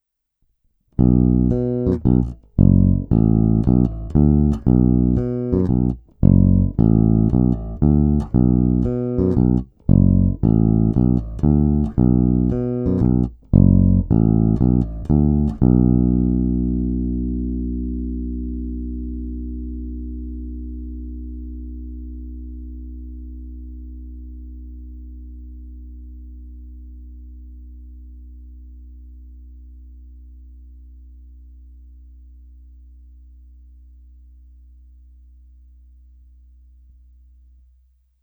Ale jinak je zvuk výborný, plný, čistý, vrčí, ale je podstatně hodnější než naprděný Music Man.
Není-li uvedeno jinak, následující nahrávky jsou provedeny rovnou do zvukové karty korekcemi na baskytaře přidanými cca na 50% (basy i výšky) a dále jen normalizovány, tedy ponechány bez postprocesingových úprav. Hráno nad snímačem.